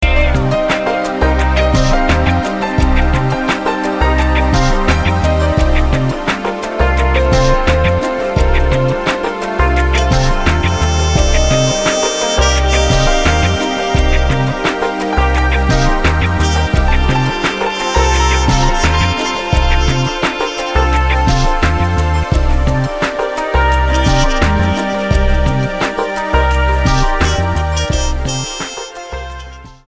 STYLE: Ambient/Meditational
keys, sax, trumpet, guitars and drum loops